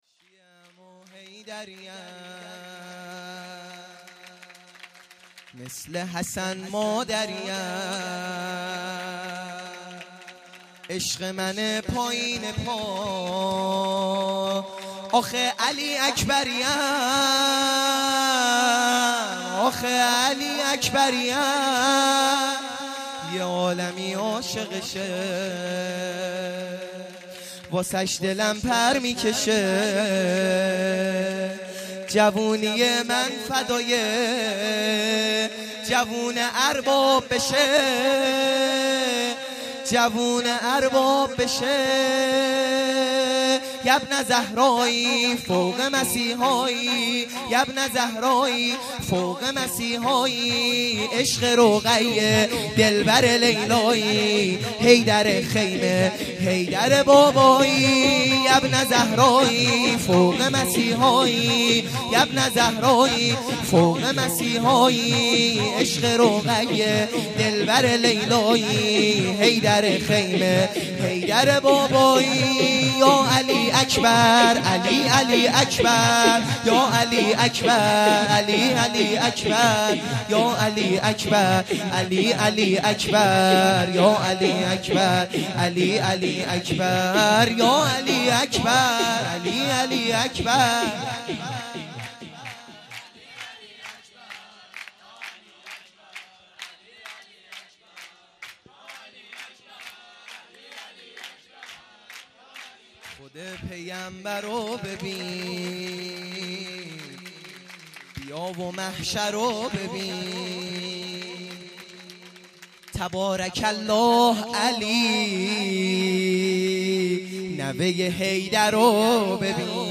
سرود | مثل حسن مادریم